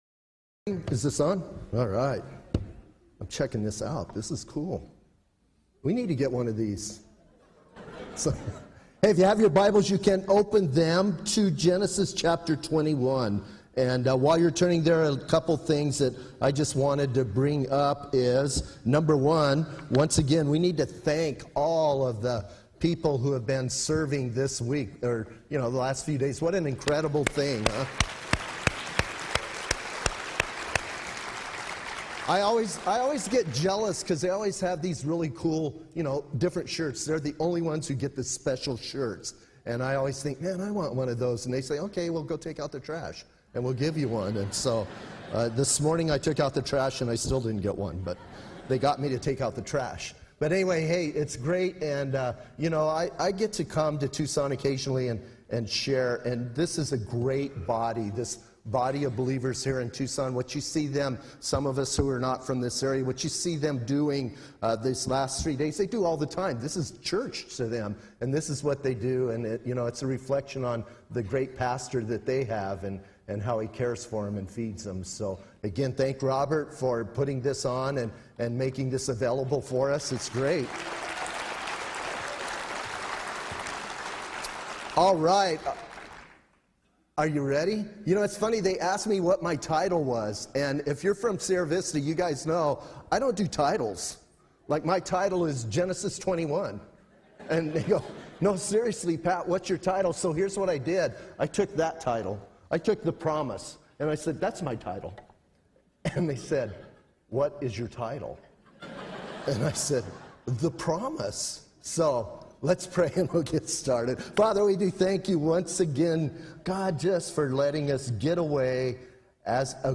at the 2015 SW Pastors and Leaders Conference